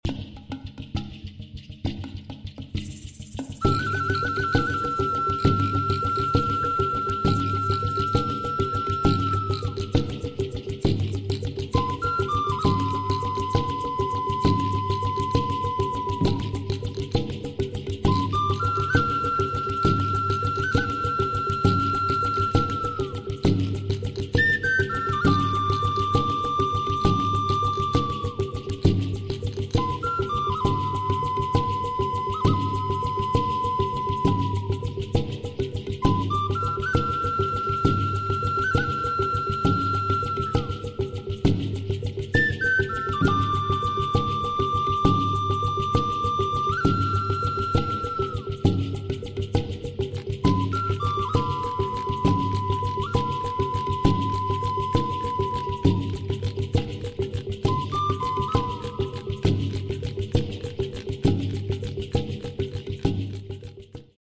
Flauta Tezcatlipoca En fotos pueden sound effects free download
Comparten varias características, como el número de orificios de digitación 4, boquilla alargada y pabellón en forma de flor.
Estas flautas presentan un sonido agudo, dadas su dimensiones.